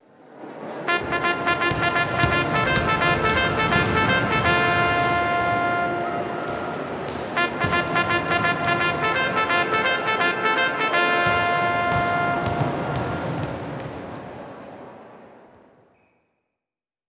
Tiempo muerto en un partido de baloncesto 02
trompetilla
trompeta
melodía
Sonidos: Música
Sonidos: Deportes